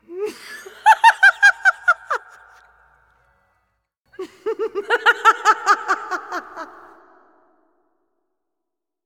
02940 insane women laughter
cackle evil female insane laugh laughter middle-aged woman sound effect free sound royalty free Funny